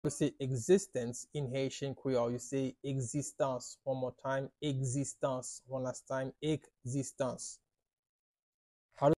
“Existence” in Haitian Creole – “Ekzistans” pronunciation by a native Haitian tutor
How-to-say-Existence-in-Haitian-Creole-–-Ekzistans-pronunciation-by-a-native-Haitian-tutor.mp3